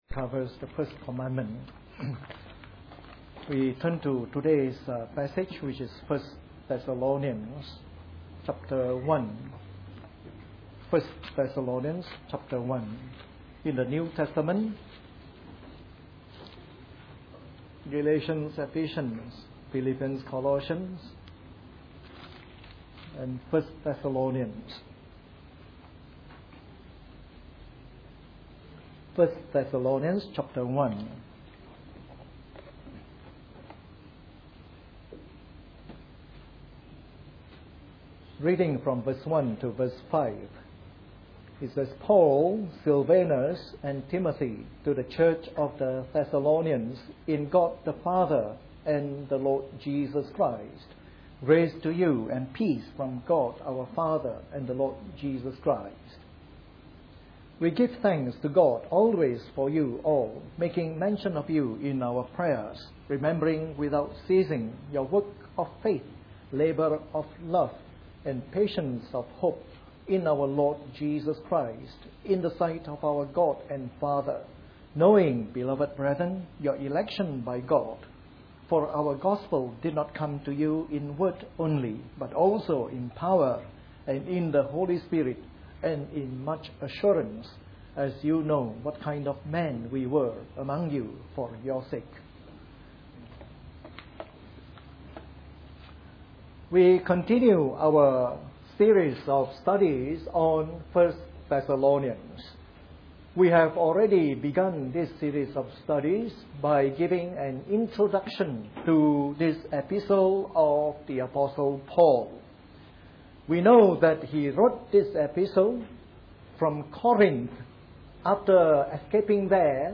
A sermon in the morning service from our new series on 1 Thessalonians.